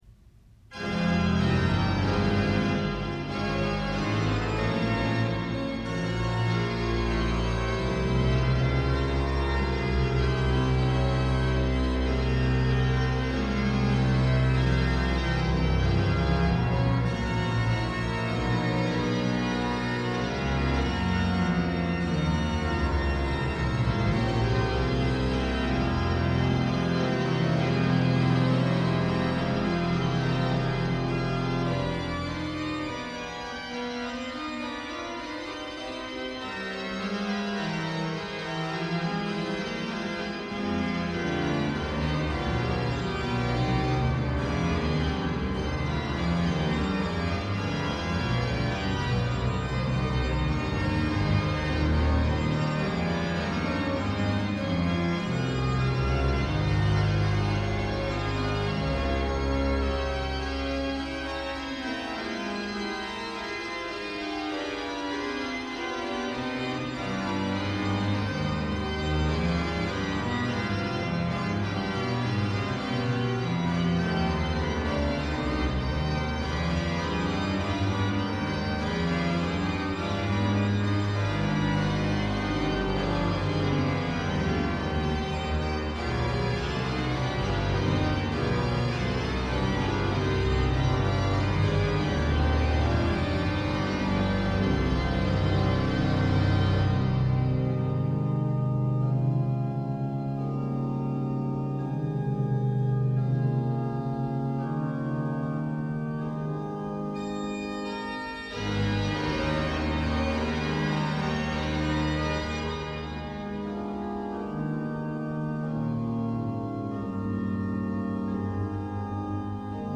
interpretando varias obras para órgano en el año 1987.
Grabaciones analógicas